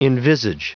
Prononciation du mot envisage en anglais (fichier audio)